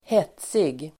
Uttal: [²h'et:sig]